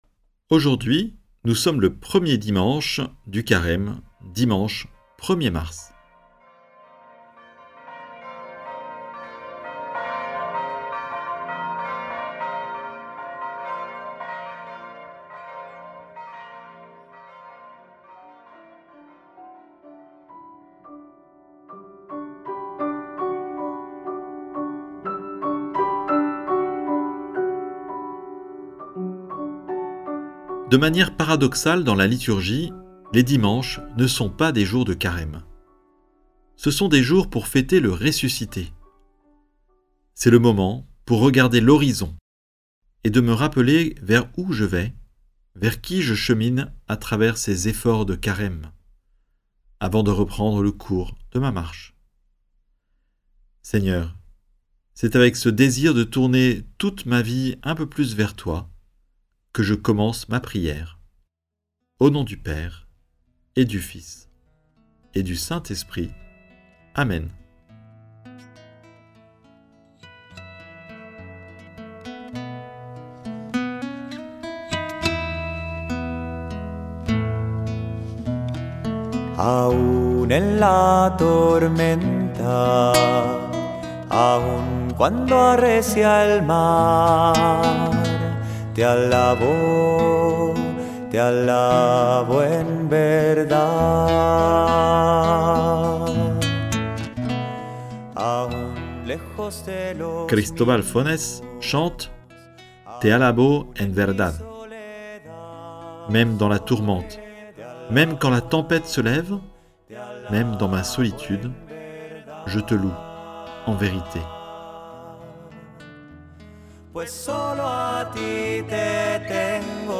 Écouter la méditation avec ‘Prie en Chemin‘ ou la suivre à son rythme avec les pistes ci-dessous.